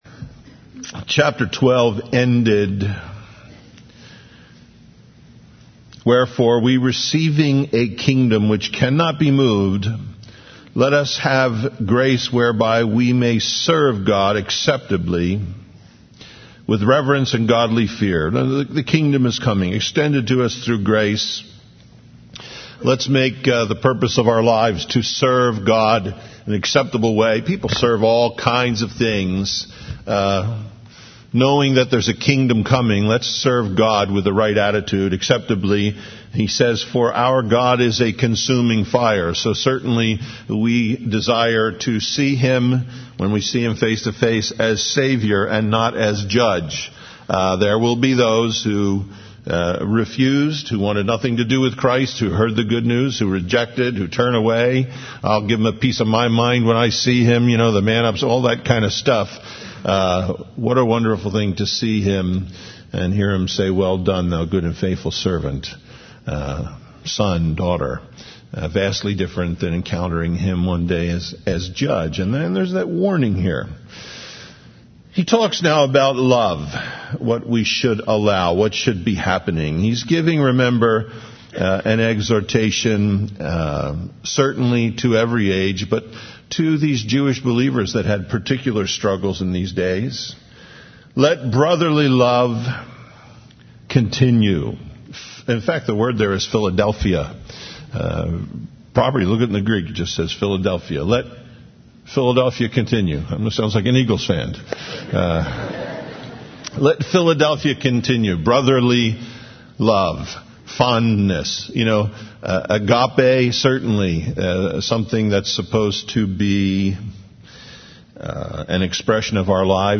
Hebrews 13:1-13:25 Listen Download Original Teaching Email Feedback 13 Let brotherly love continue.